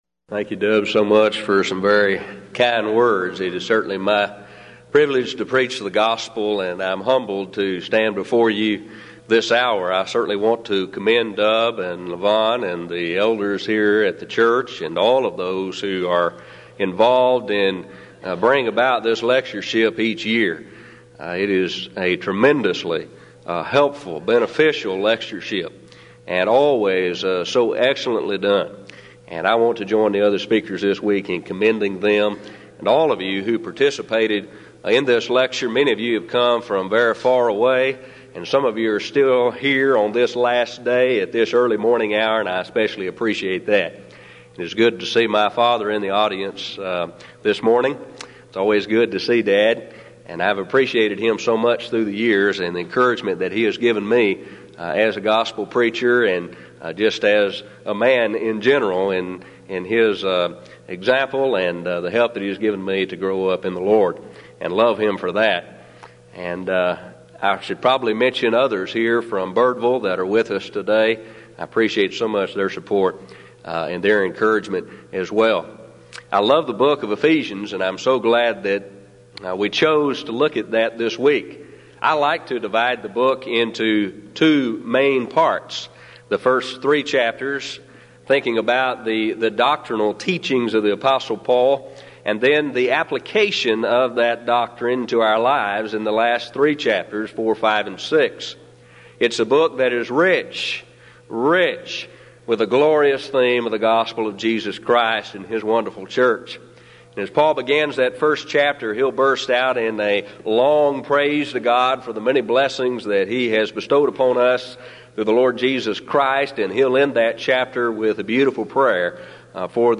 Event: 16th Annual Denton Lectures Theme/Title: Studies In Ephesians
lecture